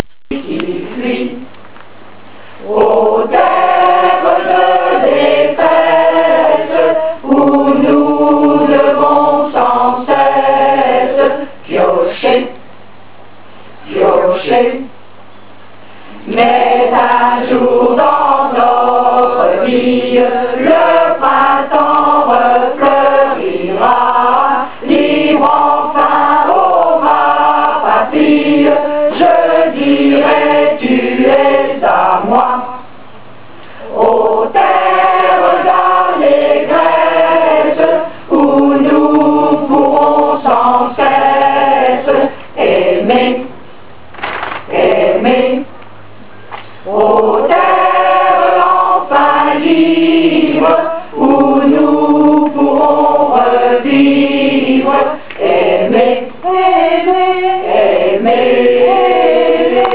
Une petite minute de chant des "voix de la Vaucouleurs"